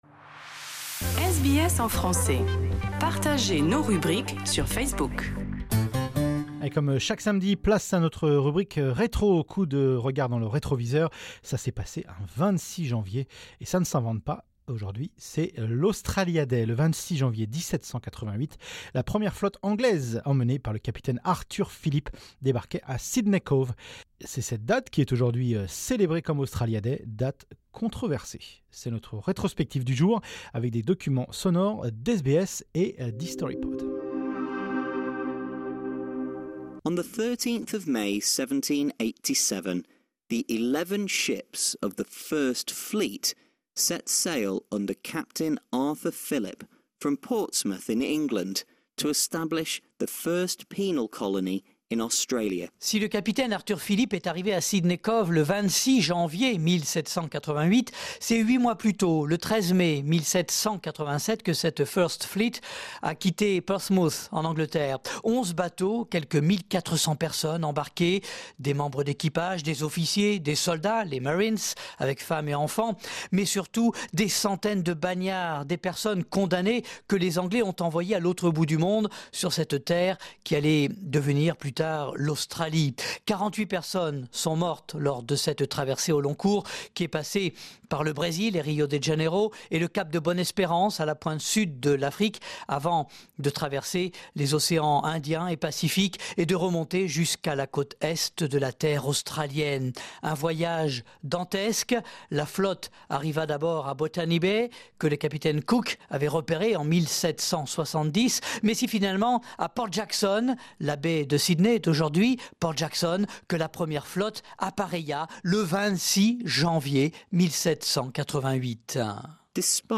C'est notre retrospective du jour avec des documents sonores d'SBS